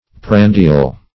Prandial \Pran"di*al\, a. [L. prandium a repast.]